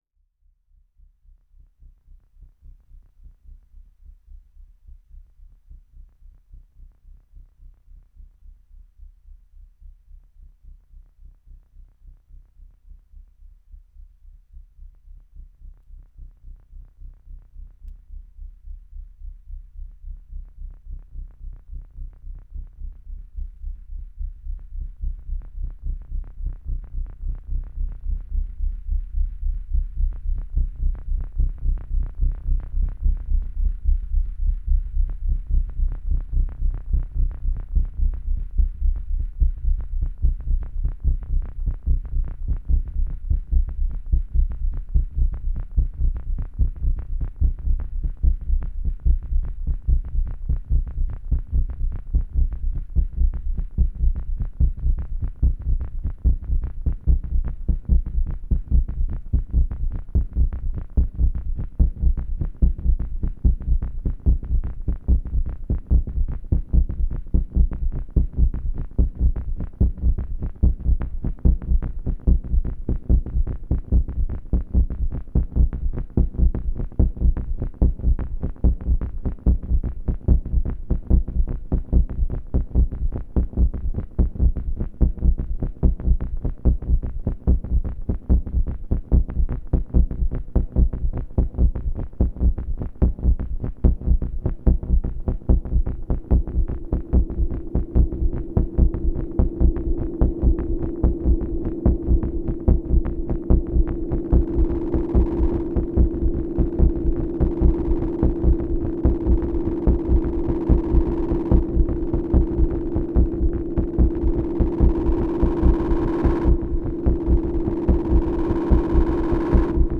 • A100 : 3 voix
• Model-D (au ruban)
Ici, le Neutron est connecté au LFO A146 du A100*. Le rapport entre ce LFO et celui du Neutron est ce qui détermine l'allure de la rythmique. Un cheveu de mouvent du potard de vitesse d'un des deux LFO change complètement la rythmique, d'où l'impossibilité de reproduire celle-ci à l'identique à moins d'un coup de chance.
Une danse un peu guerrière de quark autour d'un grand feu dans les bois du microcosme.
• Format : ogg (stéréo)